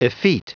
Prononciation du mot effete en anglais (fichier audio)
Prononciation du mot : effete